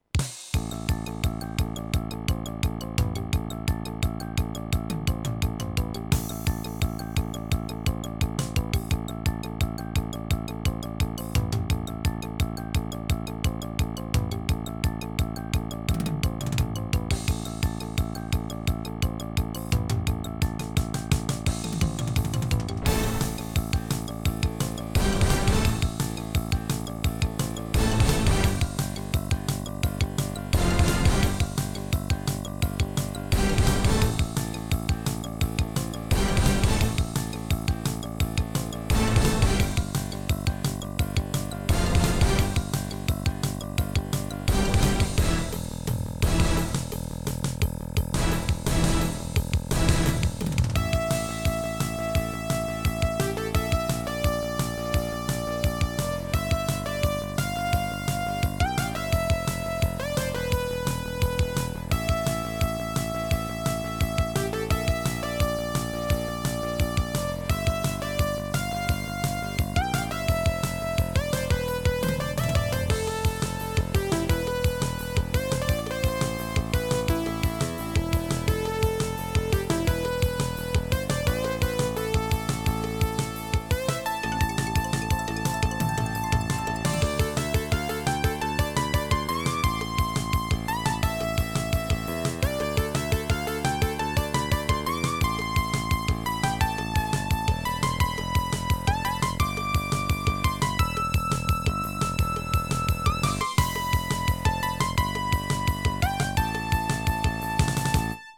SB Live